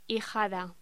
Locución: Hijada